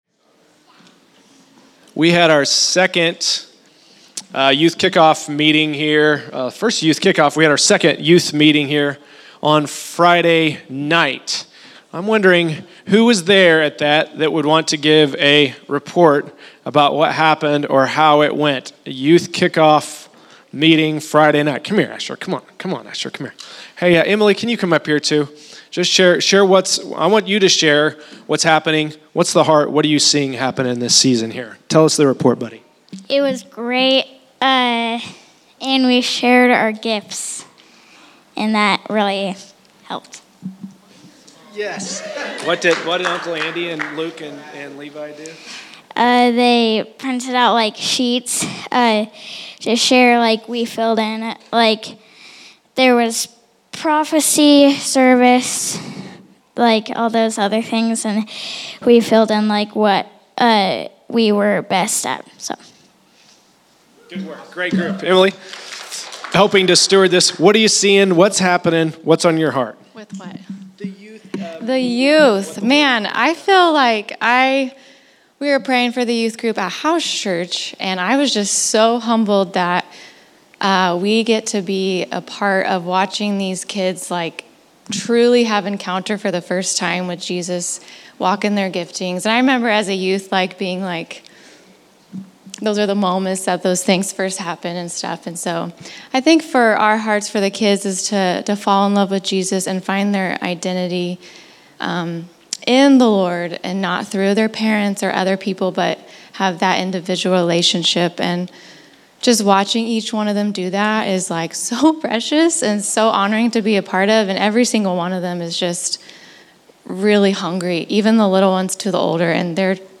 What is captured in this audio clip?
Category: Report